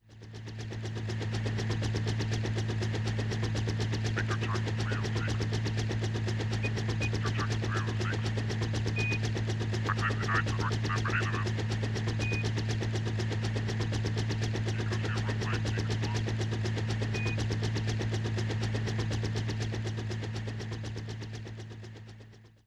Interior de un helicóptero con voces
helicóptero
ruido
Sonidos: Transportes